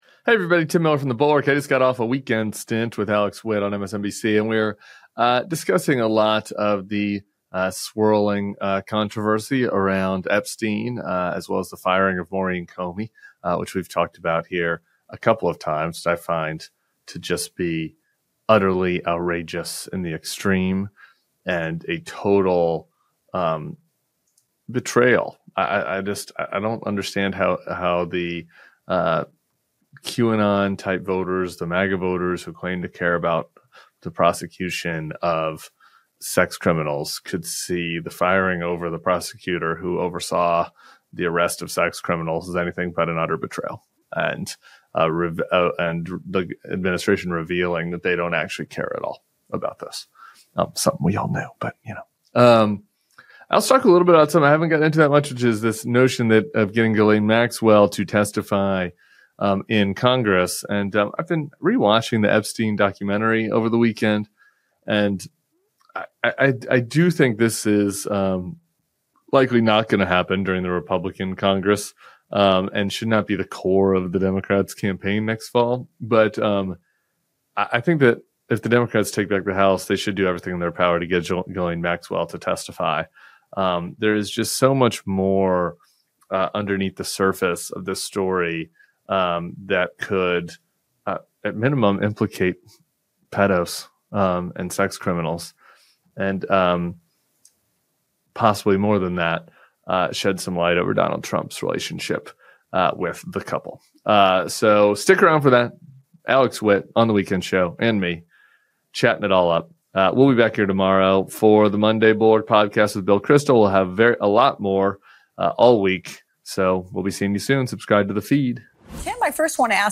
Tim Miller joins Alex Witt to unpack Trump’s ongoing legal chaos, a surreal wave of right-wing Epstein conspiracies, and why the GOP’s obsession with distraction is only making things worse.